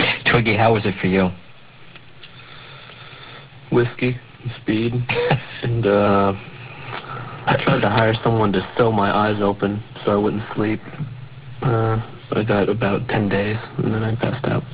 16 января 2009 Аудио того, как Твигги говорит «Виски и вмазаться» клик аудио Twiggy Ramirez Смотрите также あなた Нуремхет в стране чудес-27 маньяки укропа Да